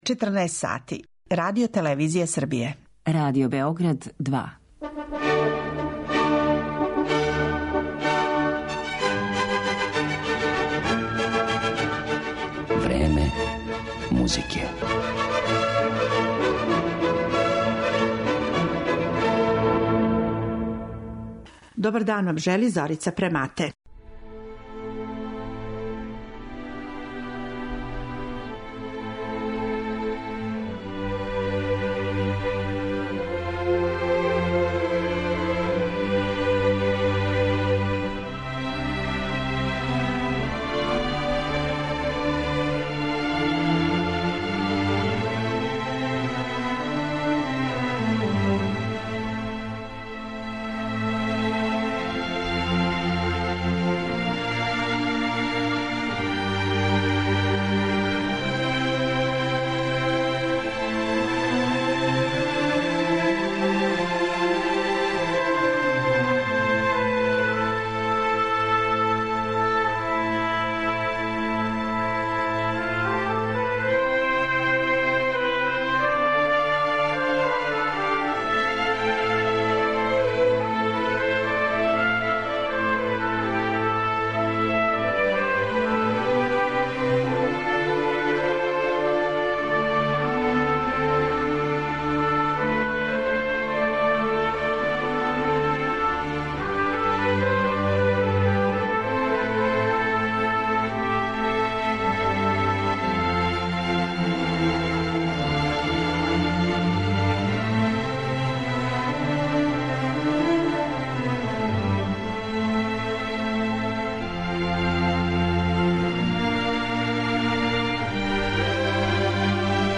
О његовом животном путу са много препрека и изненађења, као и о необичном стилу који бисмо могли назвати и "нова једноставност", али и "нови натурализам", сведочиће и Хованесова оркестарска дела која ћемо емитовати у емисији, дела која данас стичу све више присталица у свету.